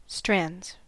Ääntäminen
Ääntäminen US Haettu sana löytyi näillä lähdekielillä: englanti Käännöksiä ei löytynyt valitulle kohdekielelle. Strands on sanan strand monikko.